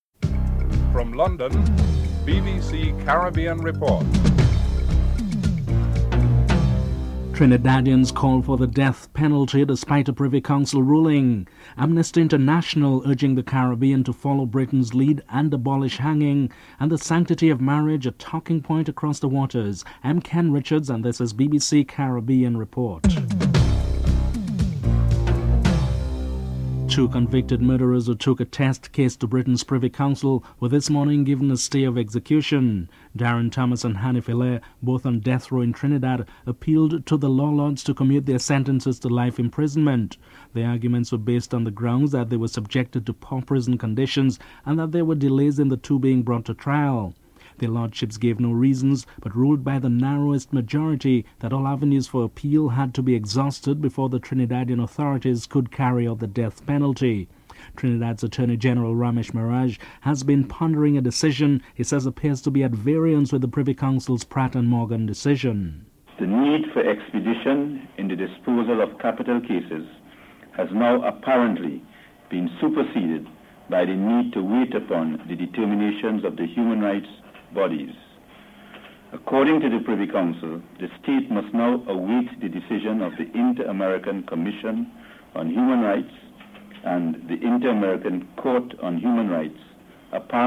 The British Broadcasting Corporation
Views are solicited from Bahamians on the streets (13:07 – 15:24)